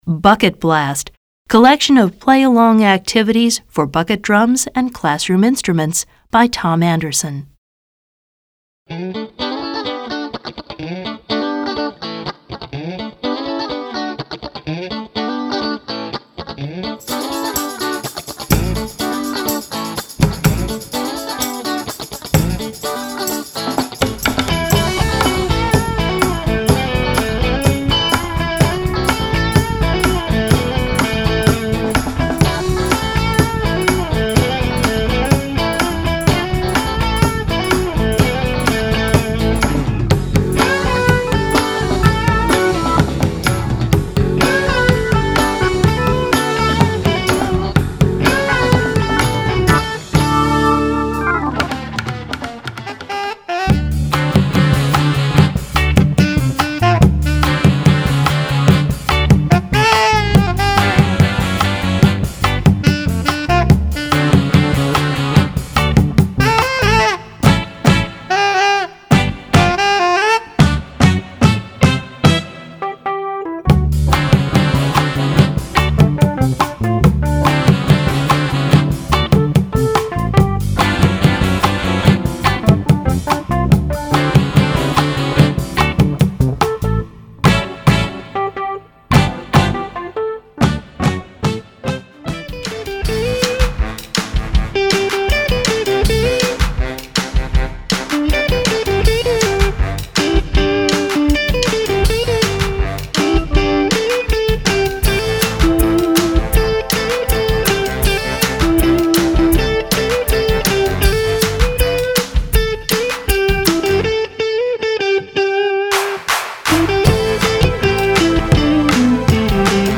General Music Classroom Instrumental